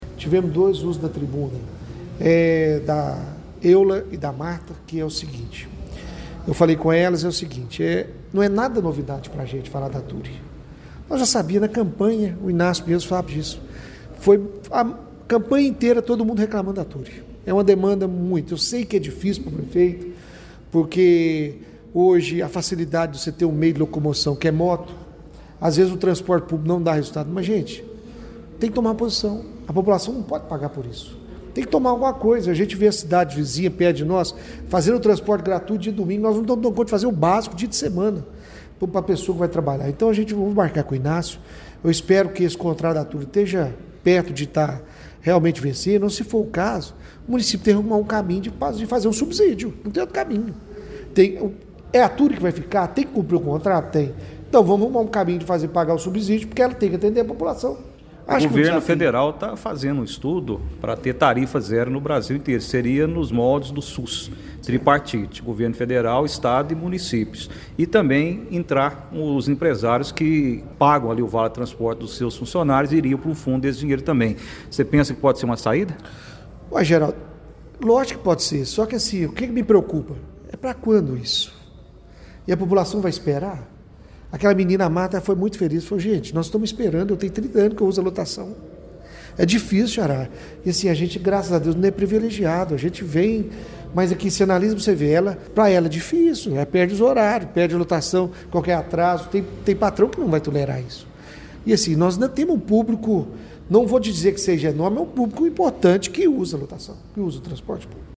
A 7ª Reunião Ordinária da Câmara Municipal de Pará de Minas, realizada nesta terça-feira (03), foi marcada por fortes críticas ao transporte coletivo urbano.